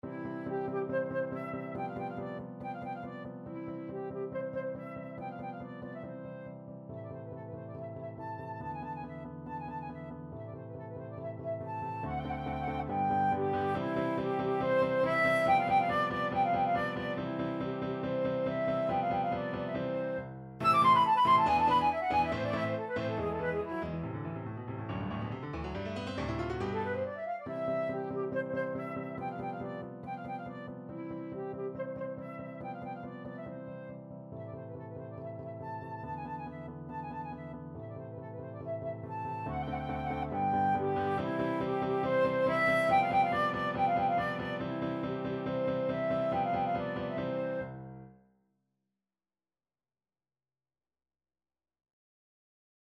2/4 (View more 2/4 Music)
C major (Sounding Pitch) (View more C major Music for Flute )
~ = 140 Allegro vivace (View more music marked Allegro)
Flute  (View more Intermediate Flute Music)
Classical (View more Classical Flute Music)